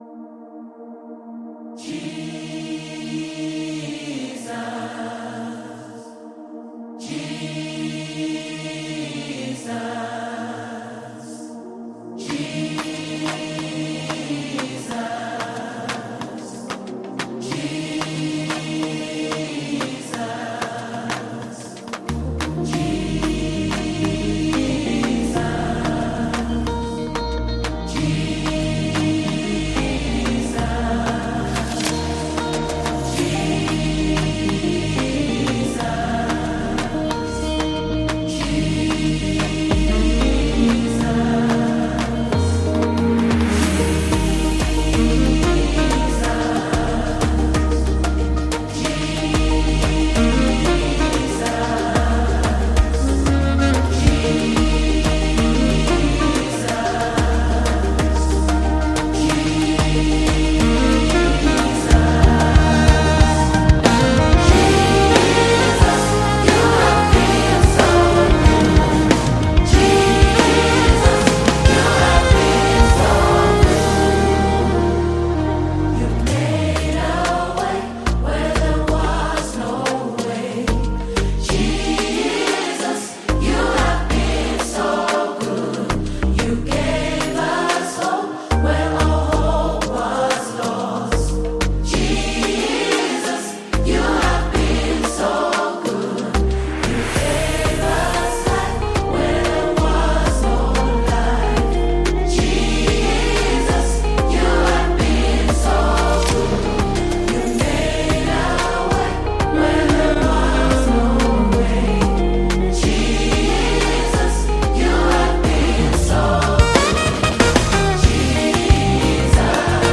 a team of modern Christian musicians with a kingdom mindset
" an uplifted hymn of praise and devotion